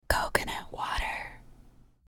(Girl Whispering) - Coconut Water | TLIU Studios
Category: ASMR Mood: Relax Editor's Choice